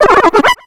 Cri de Tortipouss dans Pokémon X et Y.